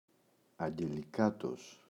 αγγελικάτος [aŋgeli’katos]